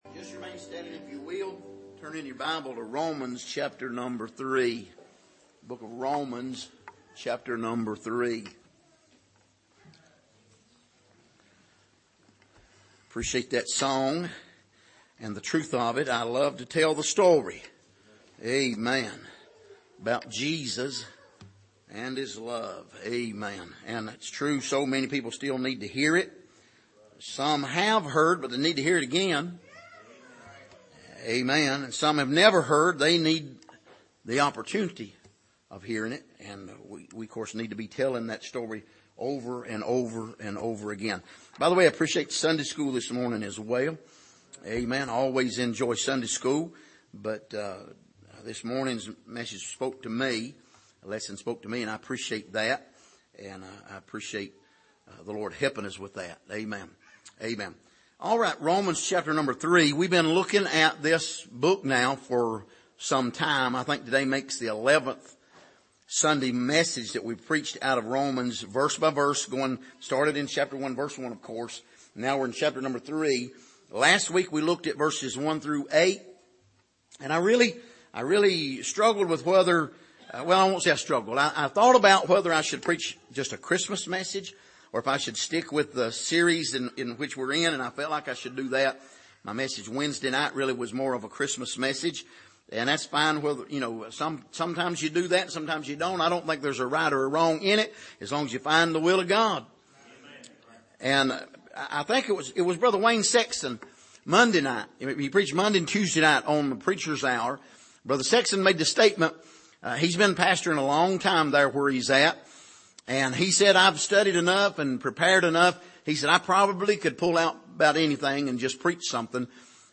Passage: Romans 3:9-20 Service: Sunday Morning